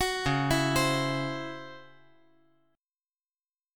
C#mM11 Chord
Listen to C#mM11 strummed